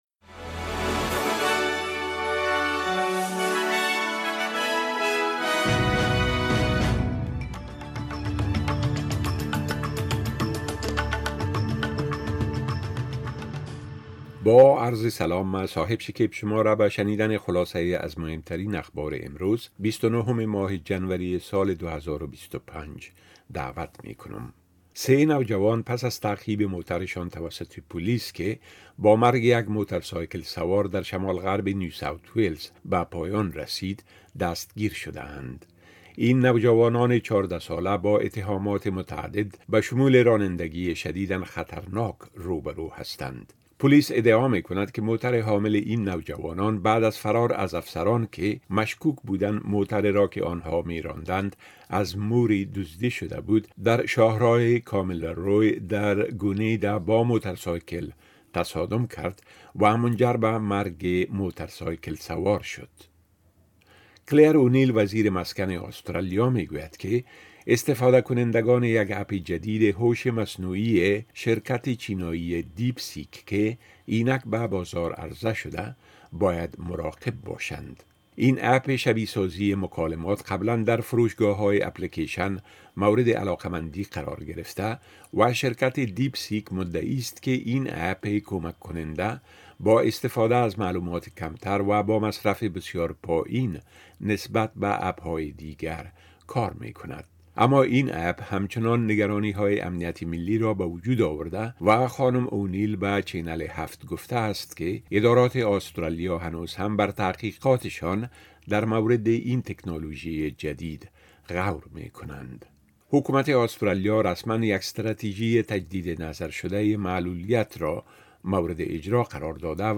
10 am News Update Source: SBS / SBS Filipino